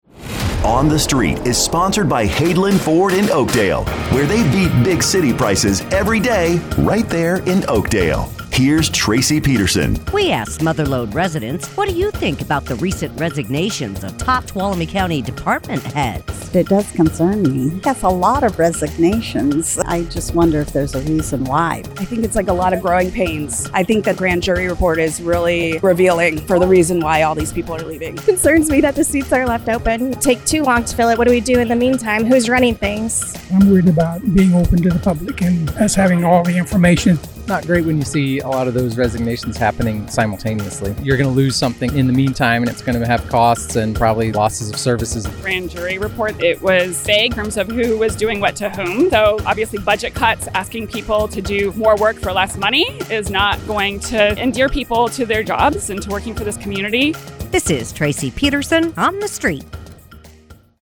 asks Mother Lode residents, “What do you think about the recent resignations of top Tuolumne County department heads?”